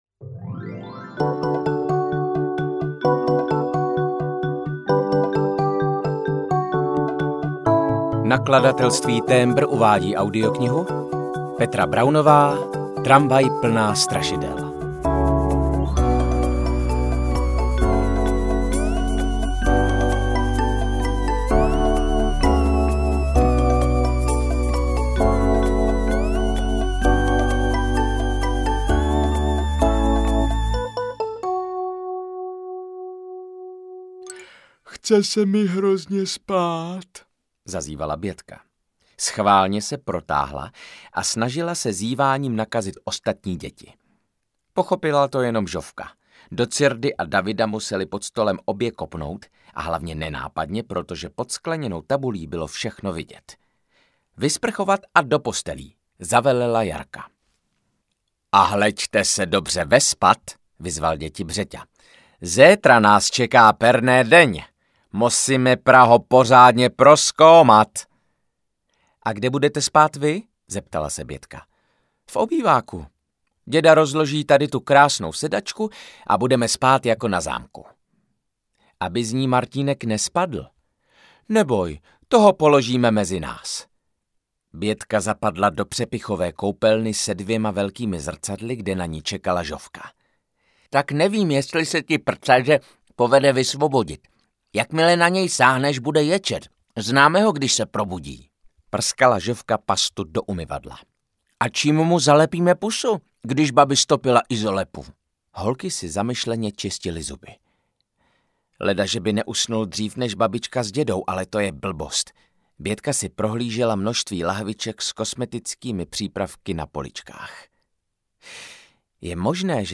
Tramvaj plná strašidel audiokniha
Ukázka z knihy
• InterpretViktor Dvořák